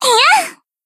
BA_V_Hanako_Swimsuit_Battle_Shout_2.ogg